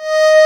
D#5 ACCORD-R.wav